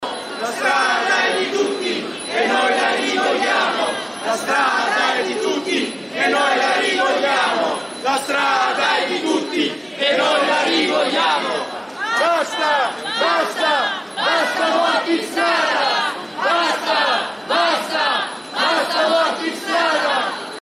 Avete appena ascoltato le voci di numerosi cittadini, scesi in piazza a Milano per chiedere più sicurezza nelle strade.